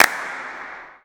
Claps
OAK_clap_mpc_01.wav